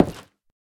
Minecraft Version Minecraft Version snapshot Latest Release | Latest Snapshot snapshot / assets / minecraft / sounds / block / nether_wood_door / toggle1.ogg Compare With Compare With Latest Release | Latest Snapshot